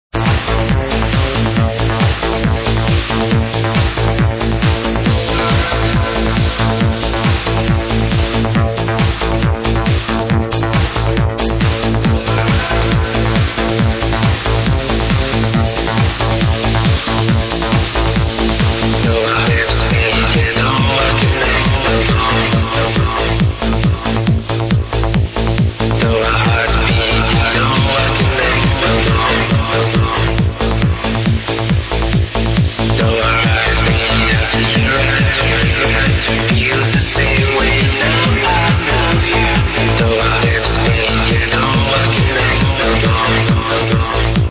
rolling stormer